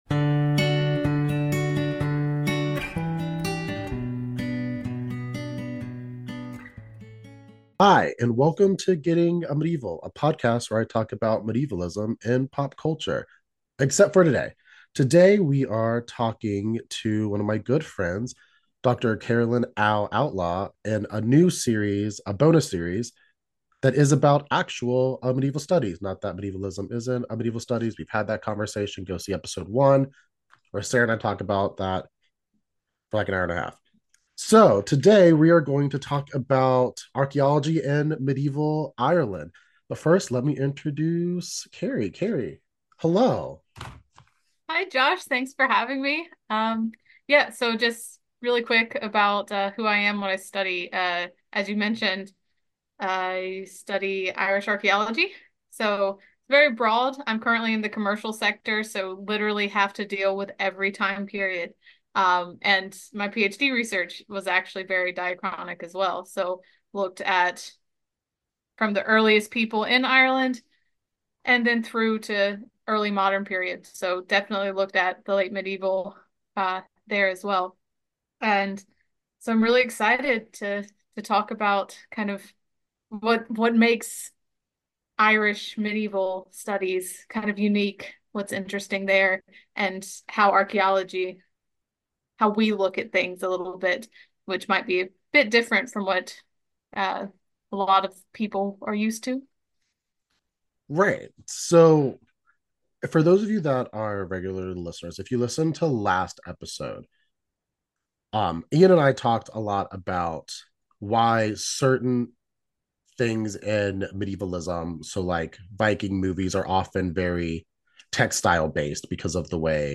Welcome to a series of bonus episode where I interview professionals in the field of Medieval Studies about their research!